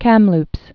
(kămlps)